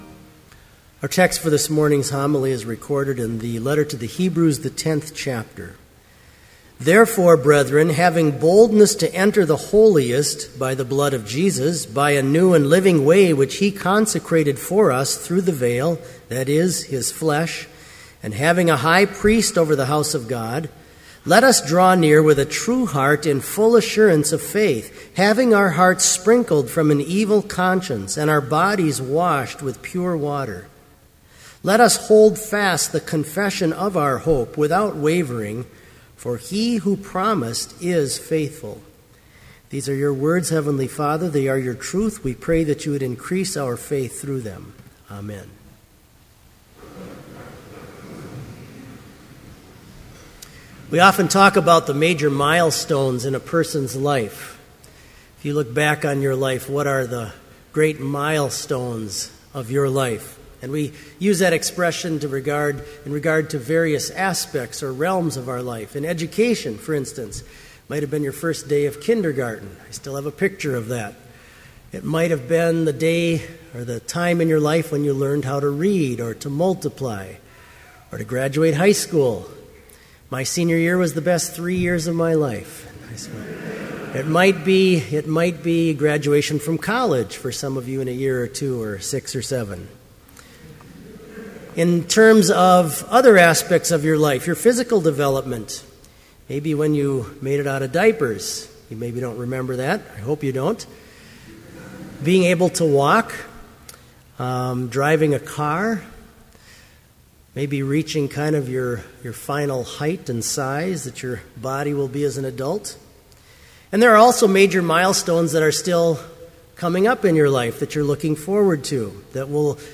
Complete service audio for Chapel - October 17, 2012